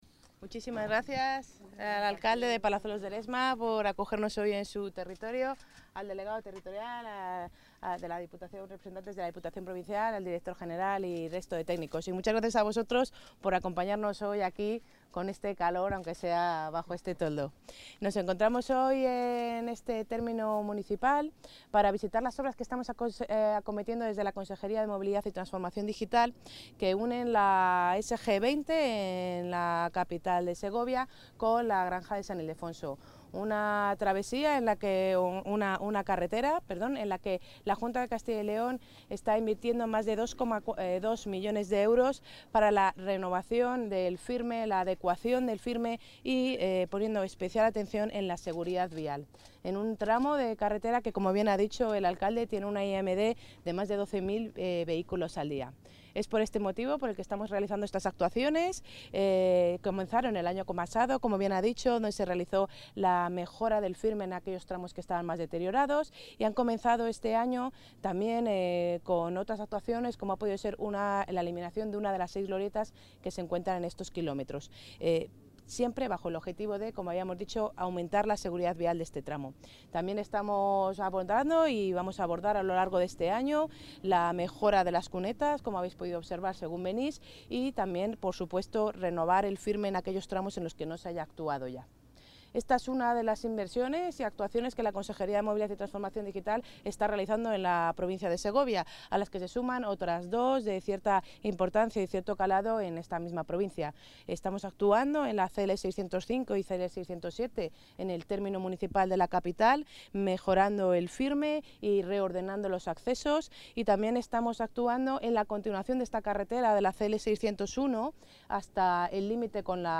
Intervención de la consejera de Movilidad y Transformación Digital.